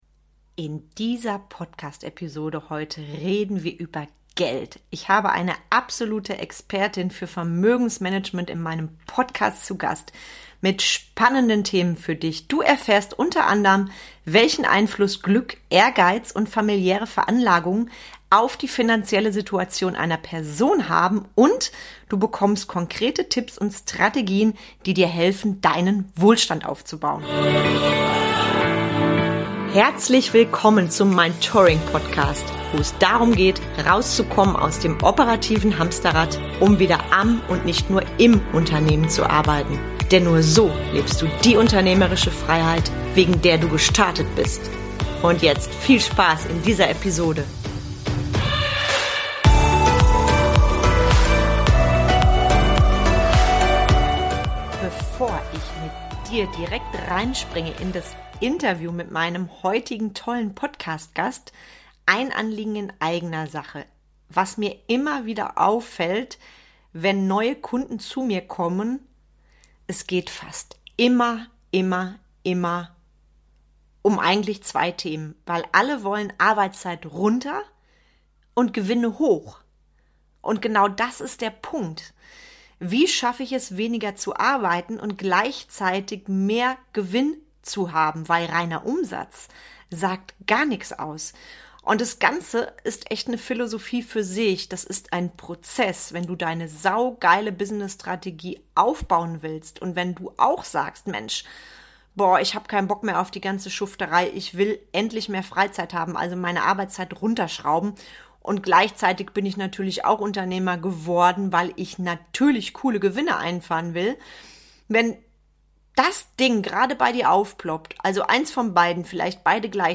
Wie gelingt dauerhafter, finanzieller Erfolg und damit die Umsetzung langersehnter Lebensträume? Meine heutige Expertin im Interview